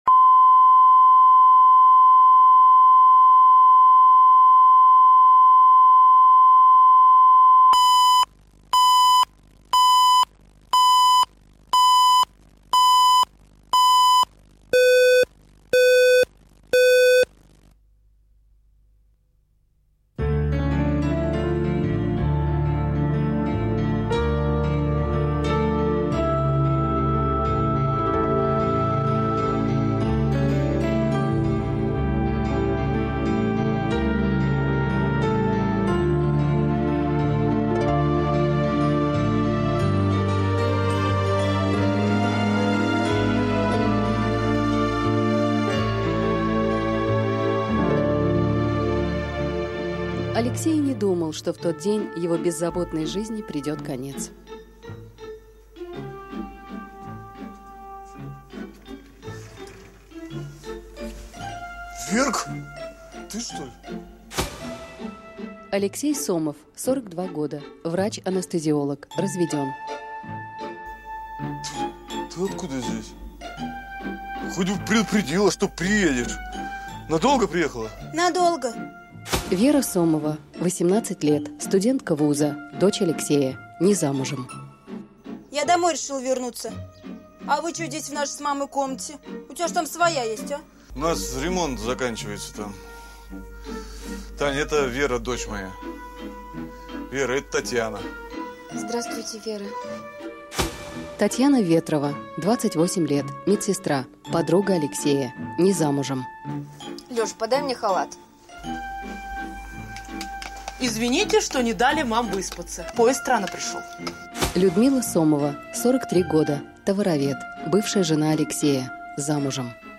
Аудиокнига Под крышей твоего дома | Библиотека аудиокниг